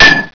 metal6.wav